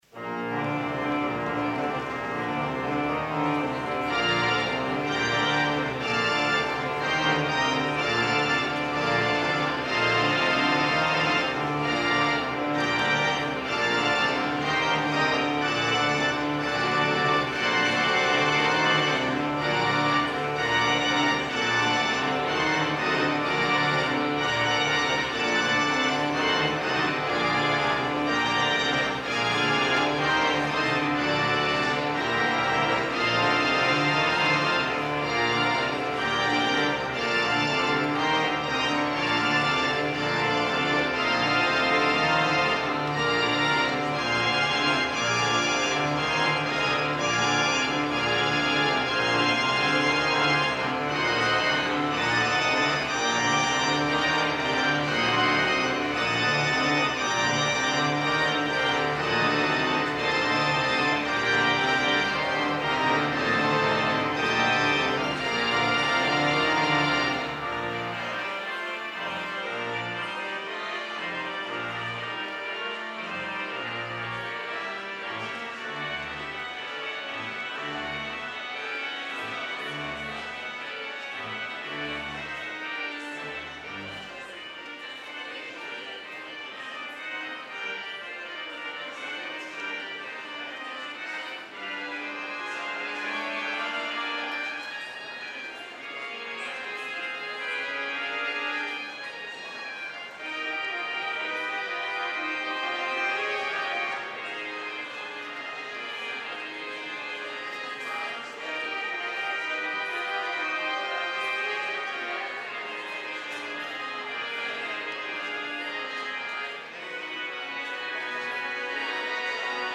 POSTLUDE Carillon
organ